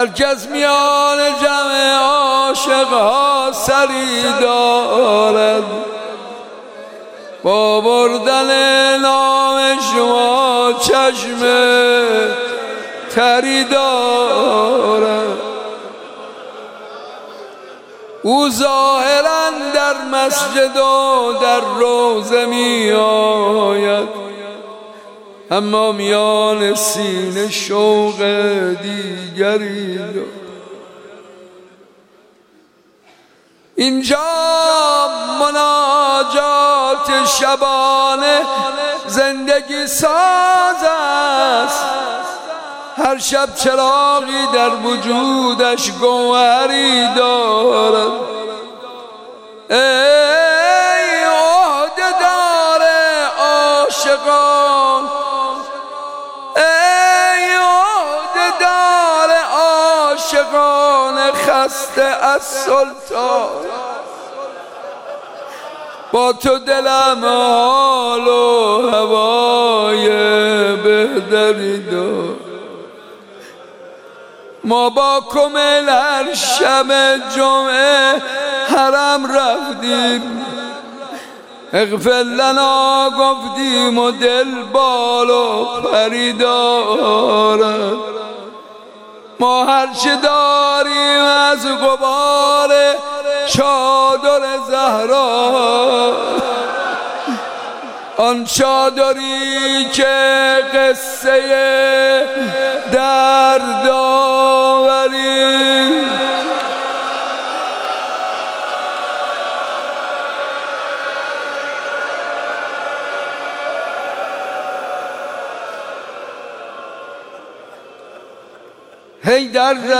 مداحی هر کس میان جمع عاشق‌ها سری دارد توسط حاج منصور ارضی در مجلس مسجد جامع حرم حضرت عبدالعظیم حسنی (ع) | 4 خرداد | 1403 اجراشده. مداحی به سبک مناجات اجرا شده است.